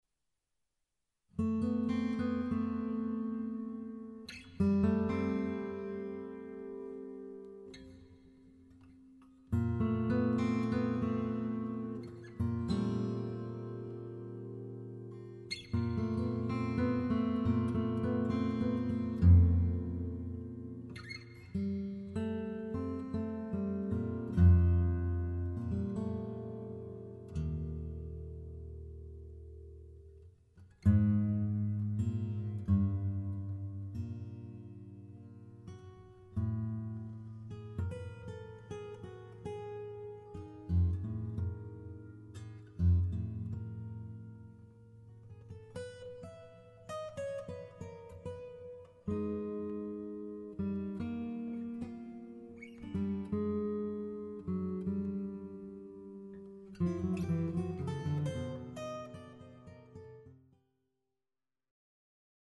for guitar and baritone guitar